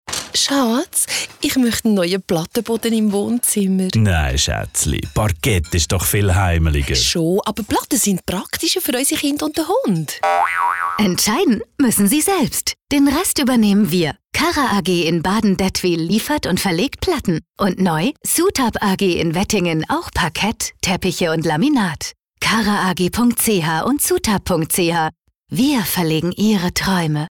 Werbung Hochdeutsch (DE)
Sprecherin.